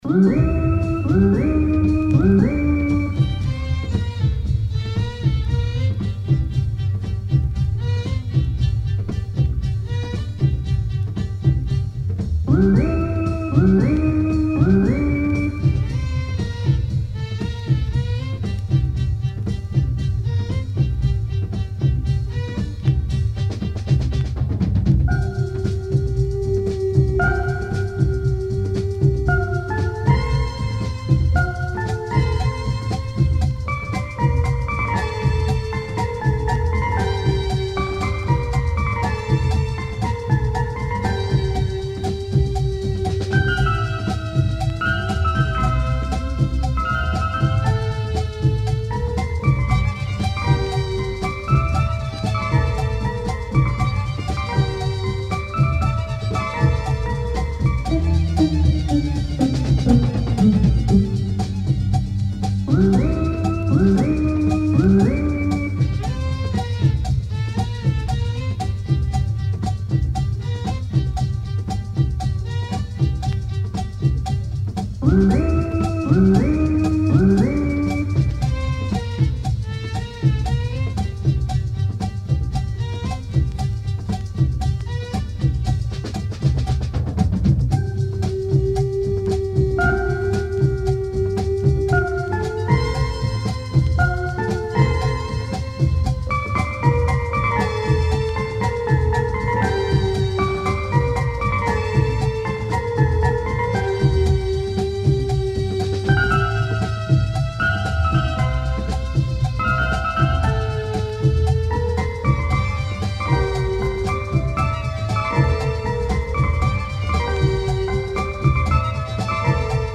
Oriental pop groove organ !